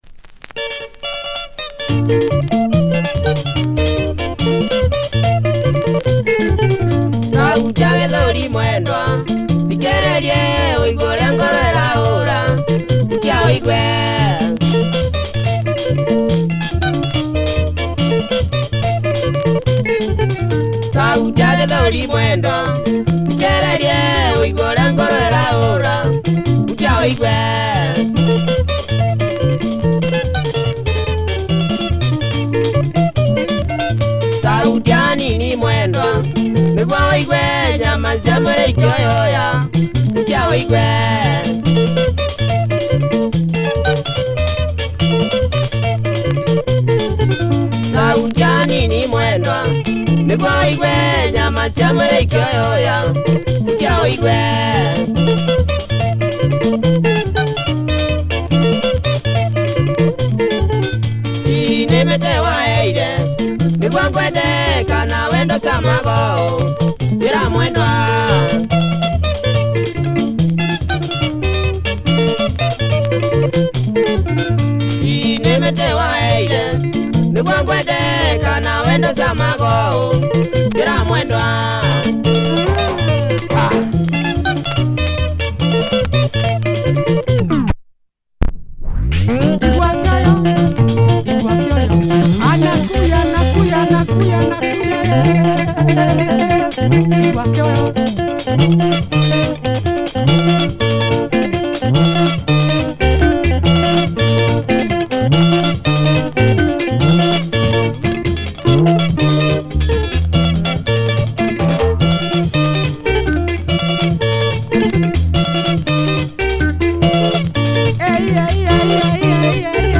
Tight and uptempo Kikuyu Benga